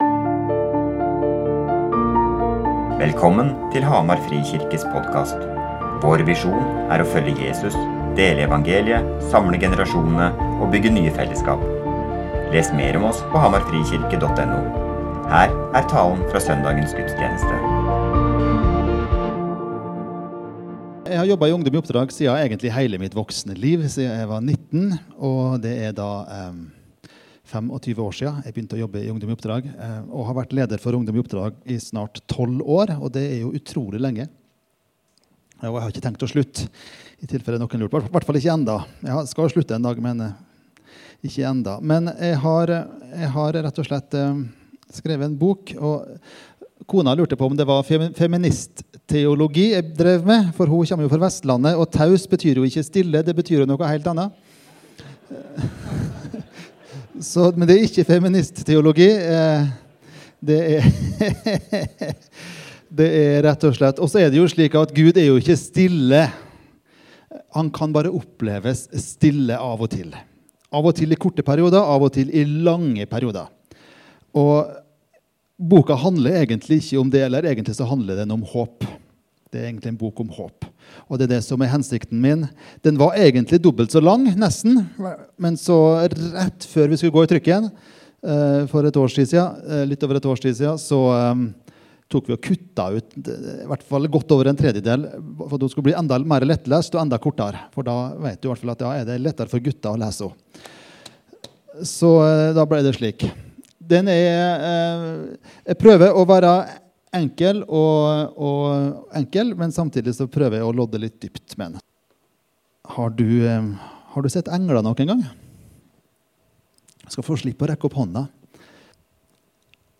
Når Gud er taus søn 25. okt 2020 Gjestetaler Evangeliet , Gud Fader Gudstjenesten https